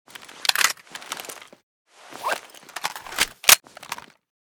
p90_reload.ogg.bak